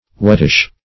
Wettish \Wet"tish\, a. Somewhat wet; moist; humid.